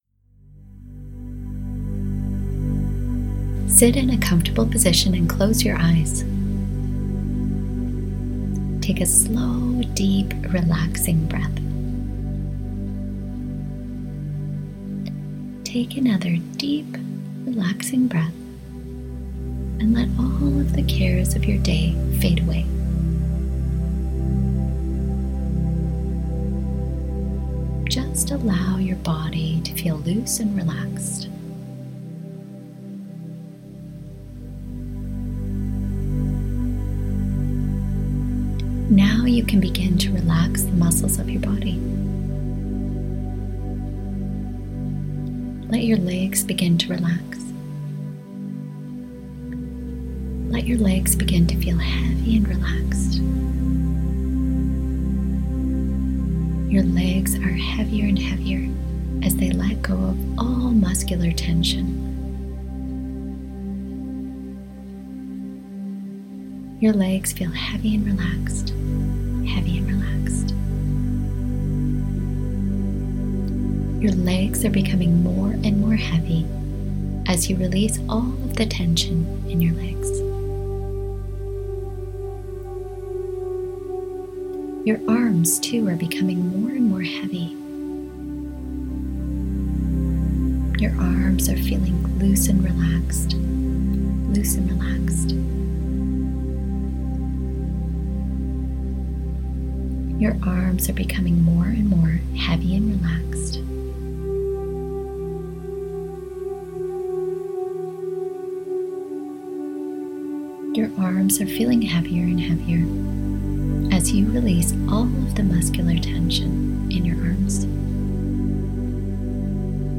this link for a free guided meditation that I made as my gift to you.